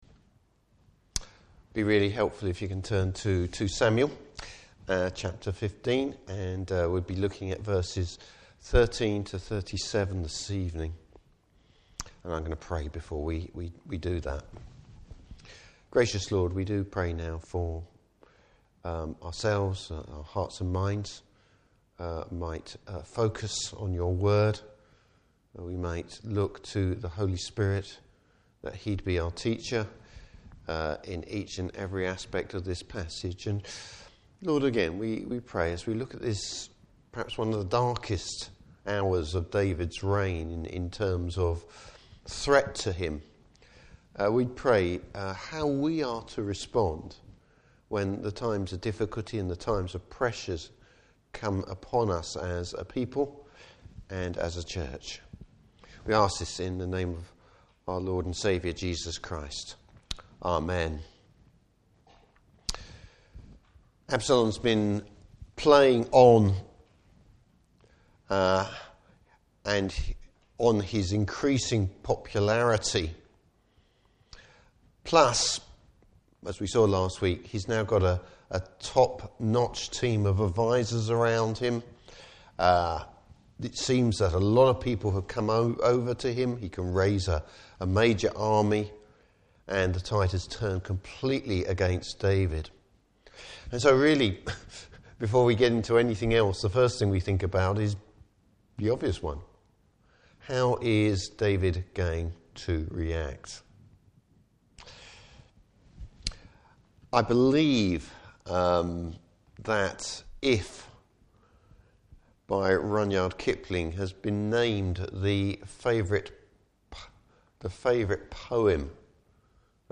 Service Type: Evening Service David looks to the Lord in difficult times.